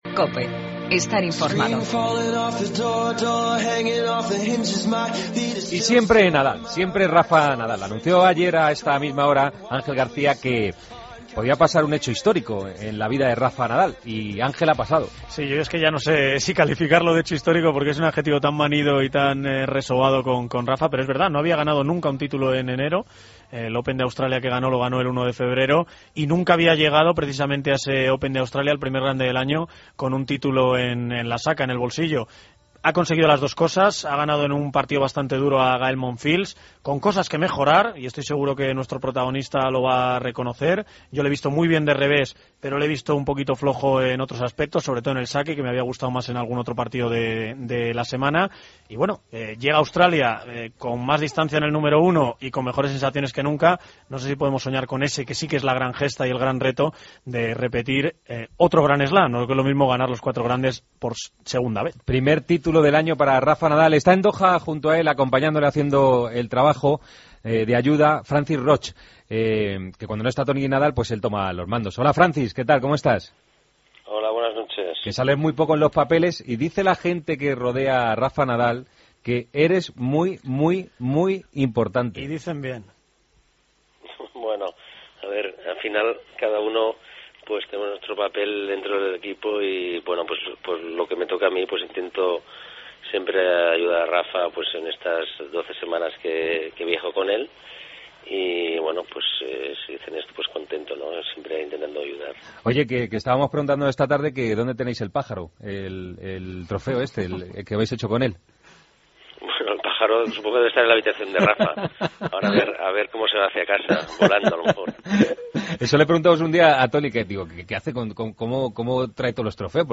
Redacción digital Madrid - Publicado el 05 ene 2014, 01:44 - Actualizado 14 mar 2023, 09:38 1 min lectura Descargar Facebook Twitter Whatsapp Telegram Enviar por email Copiar enlace Hablamos del récord conseguido por Rafa Nadal en la final de Doha, en la que ha ganado a Gael Monfils. Entrevista